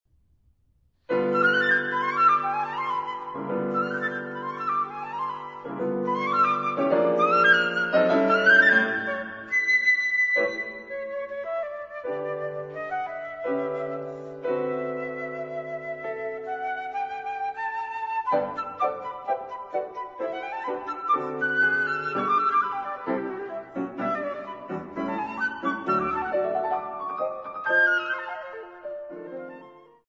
Flute
Piano